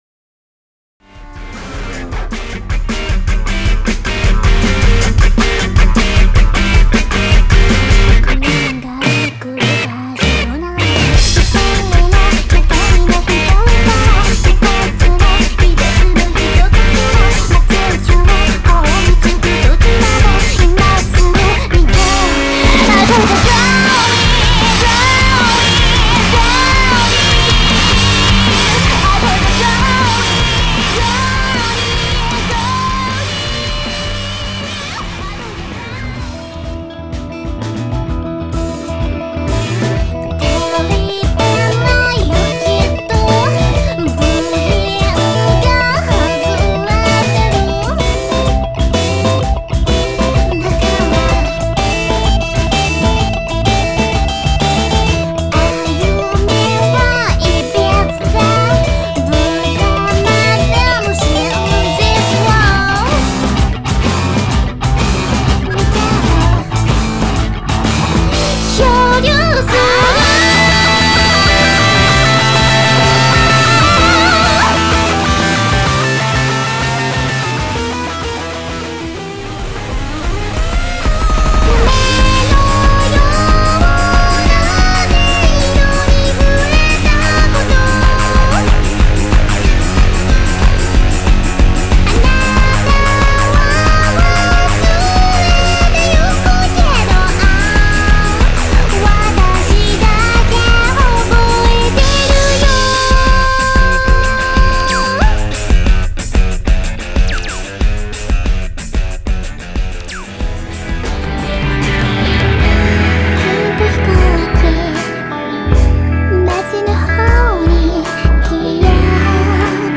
全曲クロスフェードデモ